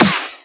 Devil's Den_Snare.wav